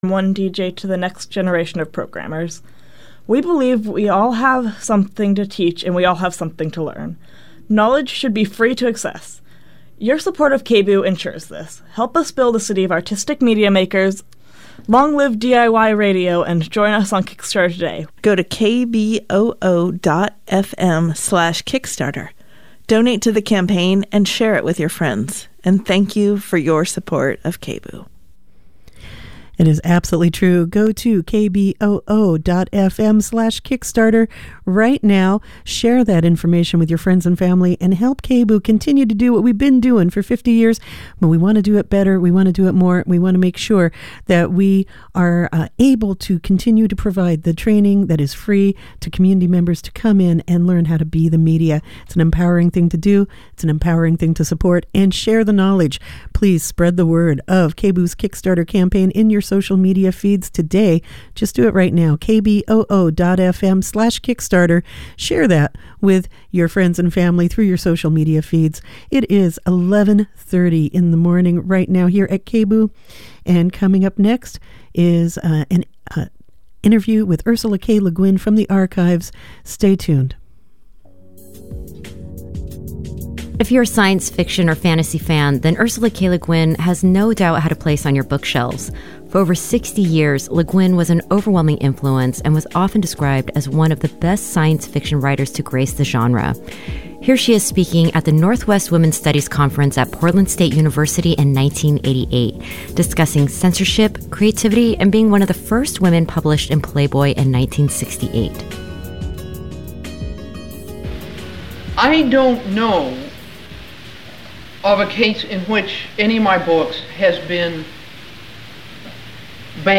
Renowned author Ursula K. Le Guin was known for more than just her books in fantasy and science fiction, she was also a critical voice of the literary world. Her insights into the publishing industry and censorship are shared here in a 1988 talk recorded at the Northwest Women's Studies Conference at Portland State University.